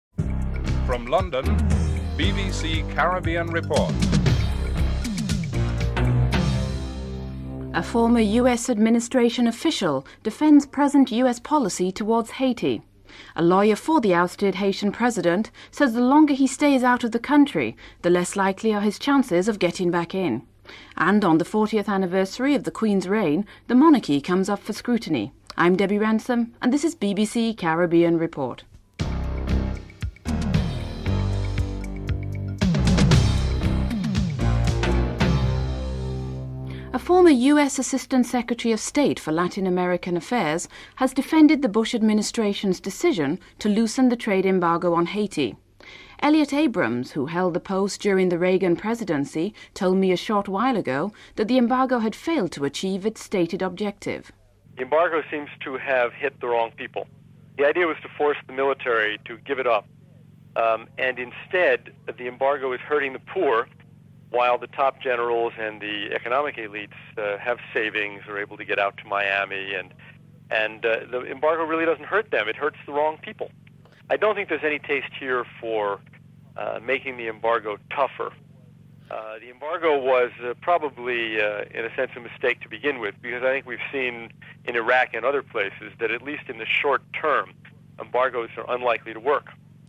1. Headlines (00:00-00:35)
2. Interview with U.S. assistant secretary of state for Latin American Affairs, Elliot Abrams who defends the Bush administration decision to relax the trade embargo against Haiti, as it had failed to achieve its stated objective (00:36-05:13)